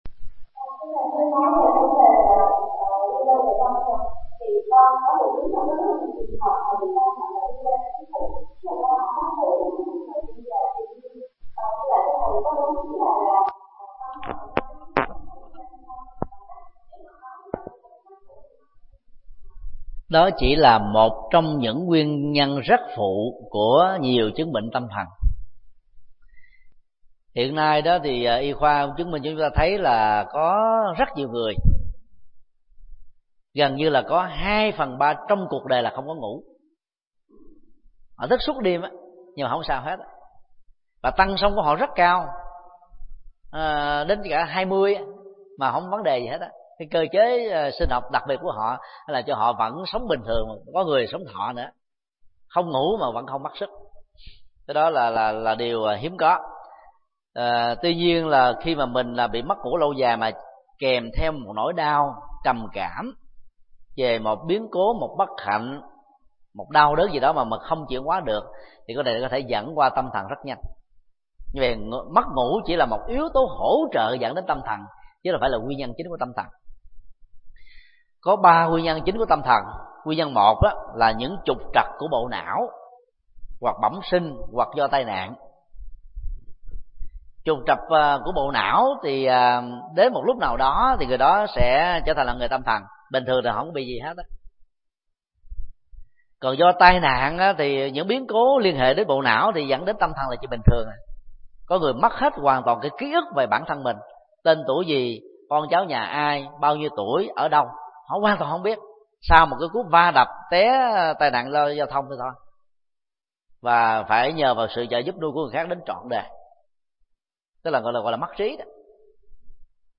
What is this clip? Vấn đáp: Nguyên nhân của bệnh tâm thần